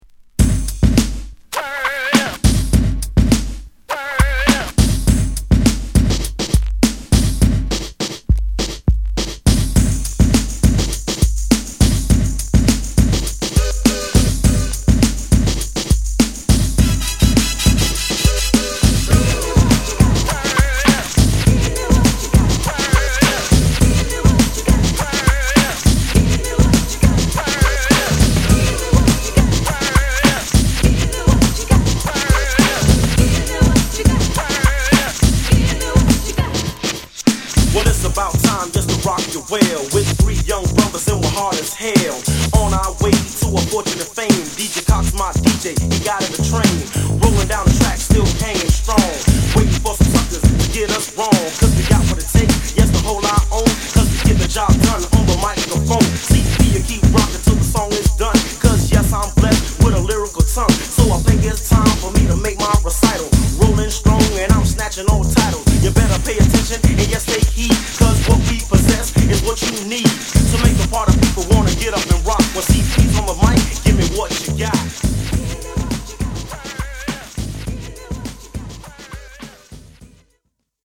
マイアミ産ミドルスクール！
叩きつけるエレクトロ調のビートで勢いマンテンの１曲！